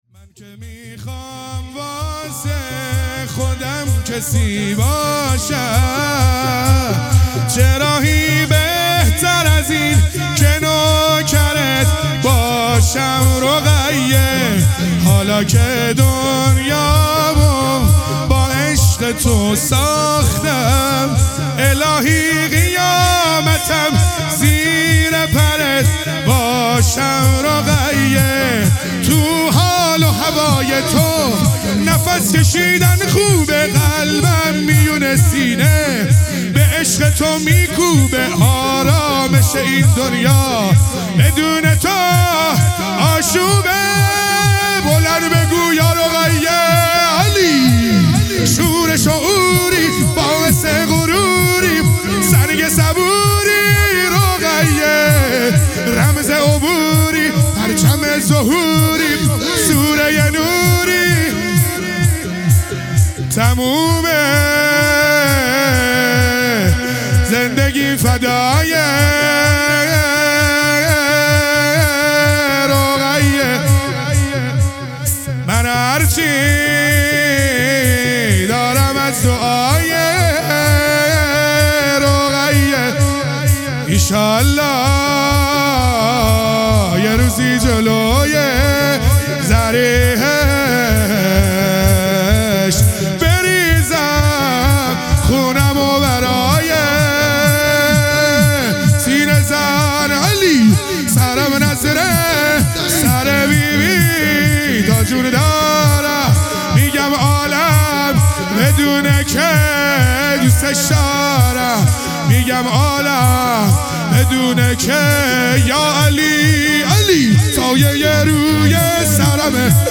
دهه اول محرم الحرام | شب چهارم | شور | من که میخوام واسه خودم